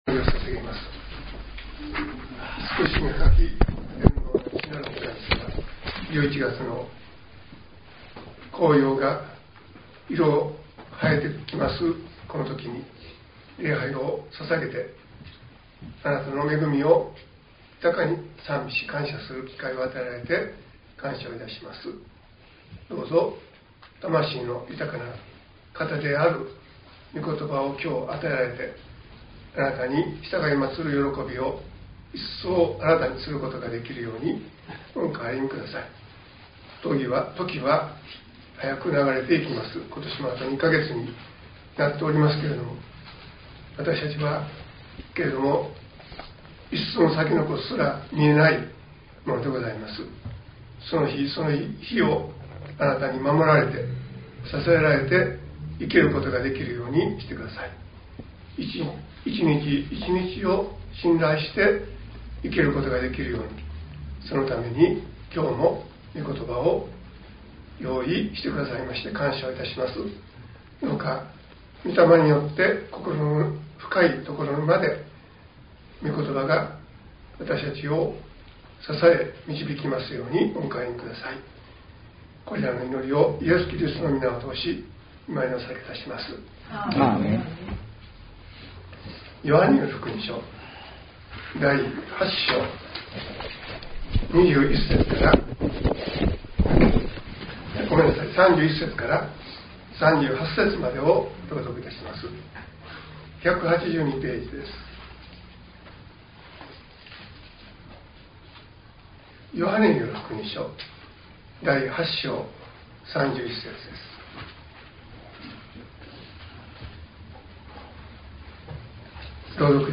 .mp3 ←クリックして説教をお聴きください。